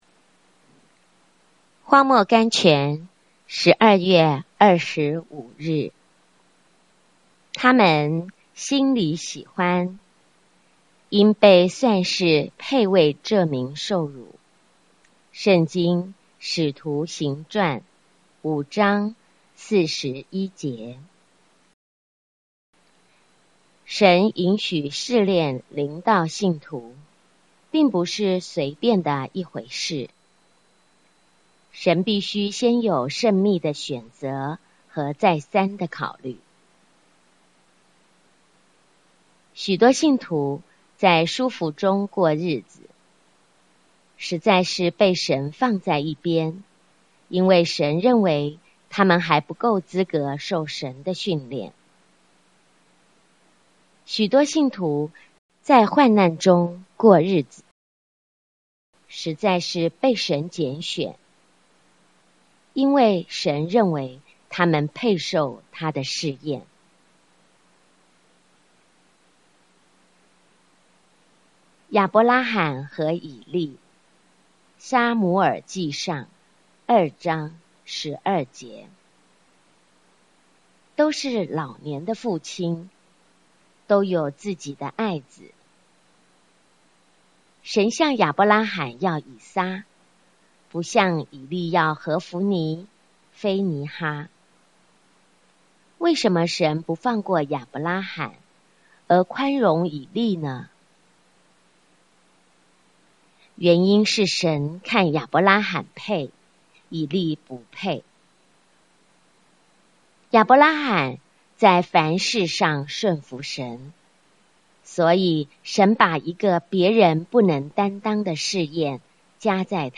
voice聆聽朗讀